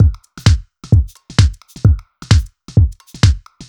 Index of /musicradar/uk-garage-samples/130bpm Lines n Loops/Beats
GA_BeatnPercE130-07.wav